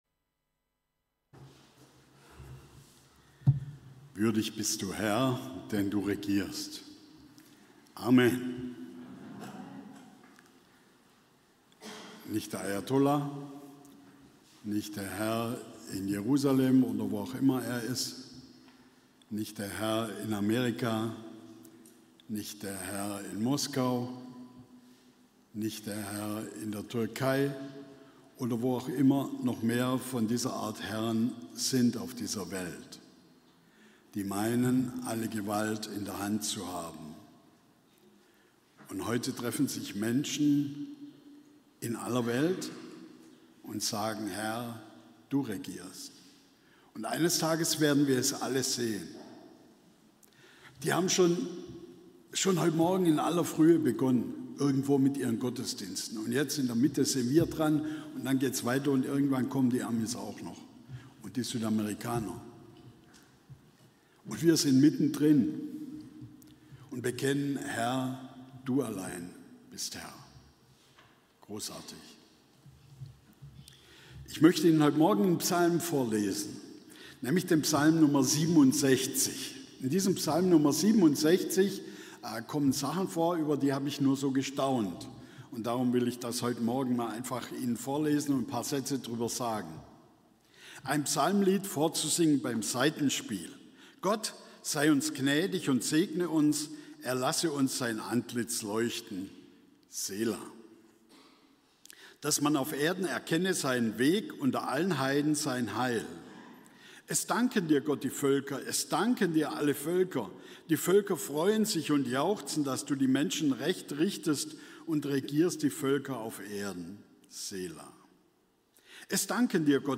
Missionsgottesdienst – Psalm 67